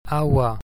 I have pronounced them between two a’s sounds everytime, so it is clearer (sometimes sounds are not that clear at the beginning or at the end of a word).
My dialect is the Northwestern Donegal one.
broad bh & mh (Donegal)
broad bh & mh (Donegal & Connemara).mp3